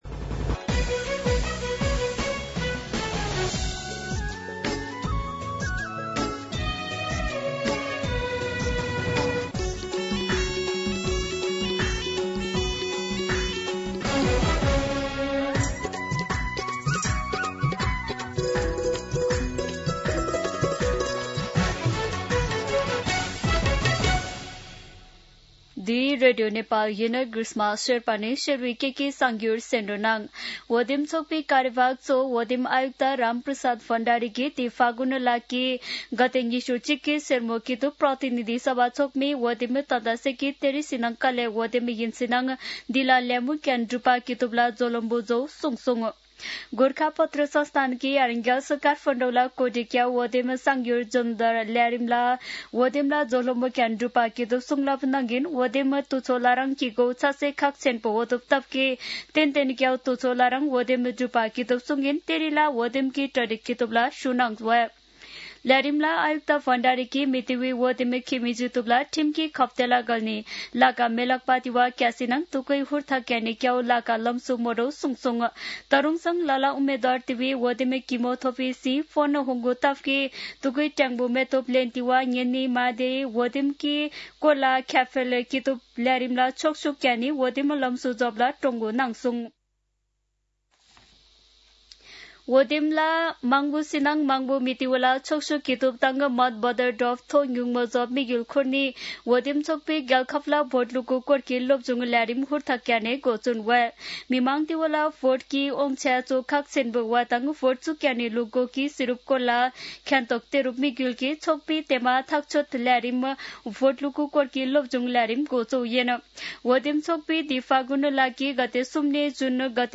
शेर्पा भाषाको समाचार : १० फागुन , २०८२
Sherpa-News-2.mp3